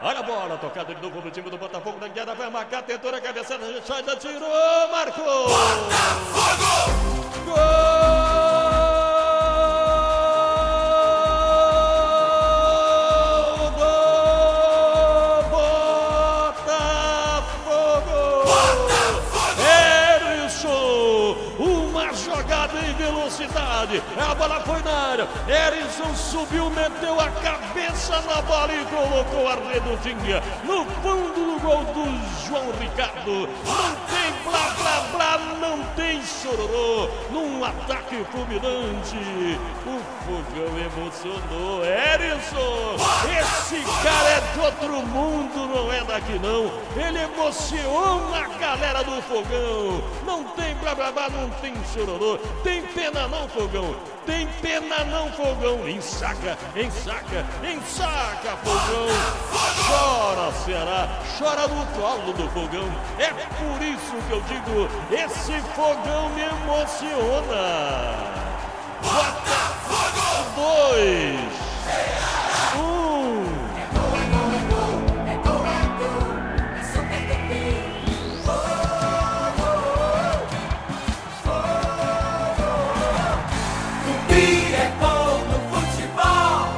narração